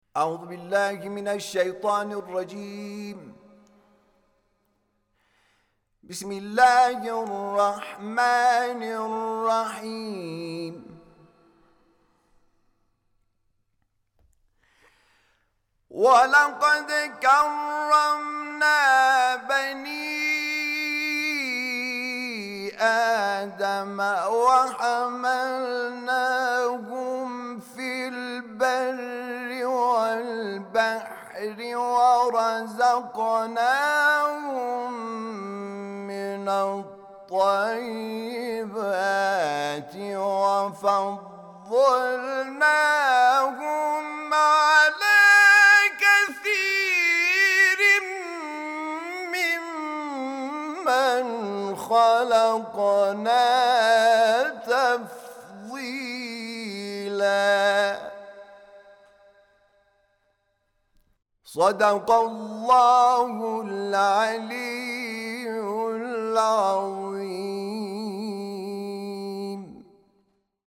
تلاوت آیه ۷۰ سوره مبارکه‌ی اسراء توسط حامد شاکر نژاد